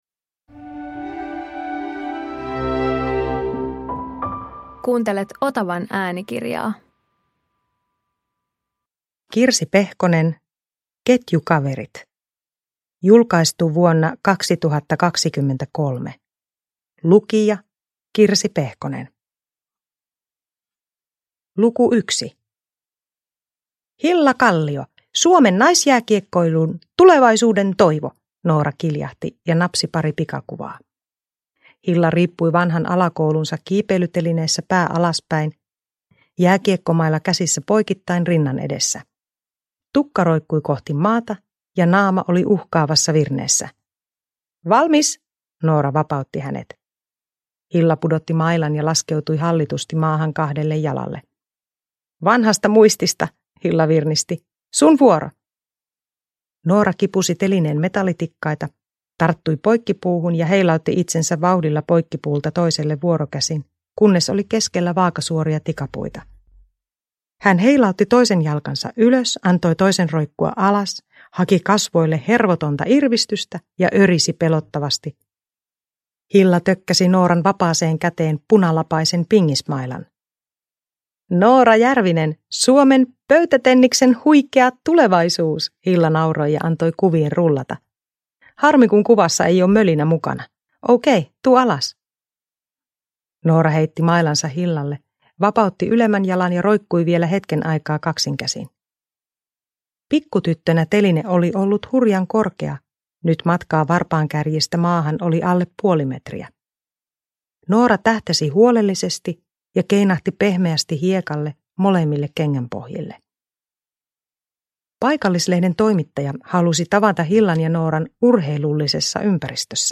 Ketjukaverit – Ljudbok